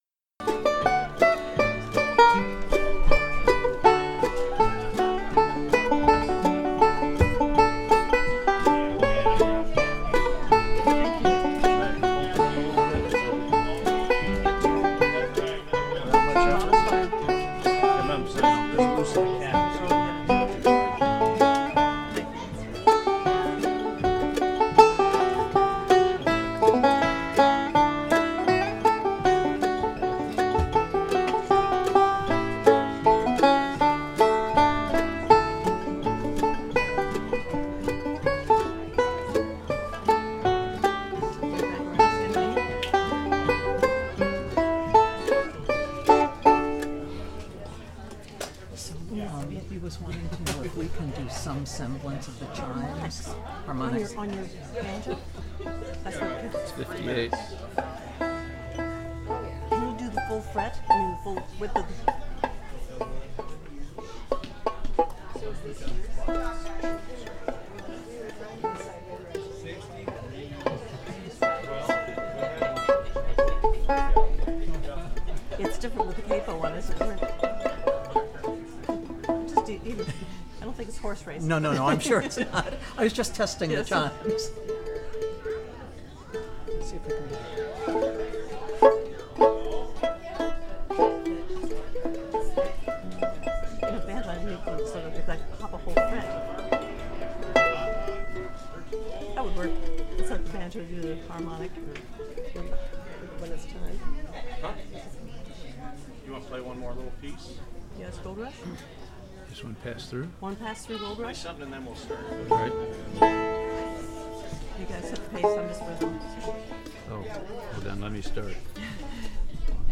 Sermon Archives – Hillsboro Presbyterian Church